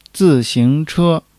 zi4-xing2-che1.mp3